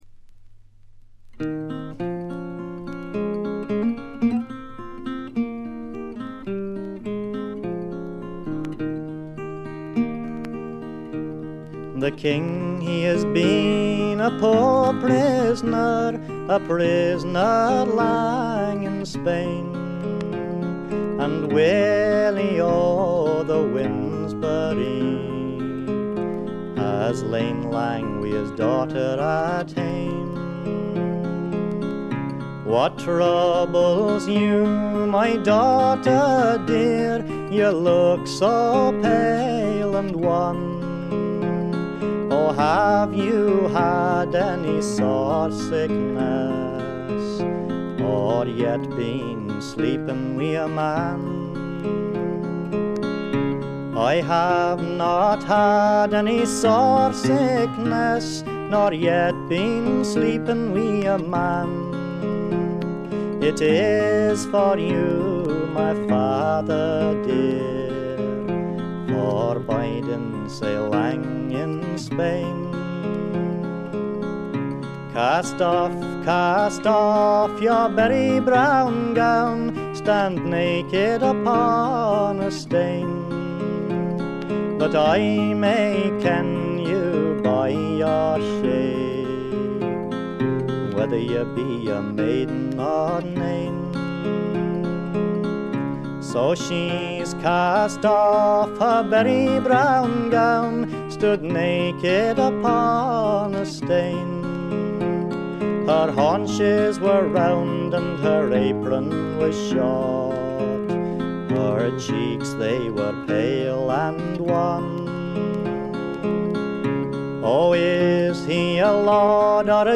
B3チリプチ少々。
試聴曲は現品からの取り込み音源です。
Vocals, Bouzouki, Tin Whistle
Vocals, Guitar, Banjo, Concertina
Vocals, Mandolin, Harmonica, Guitar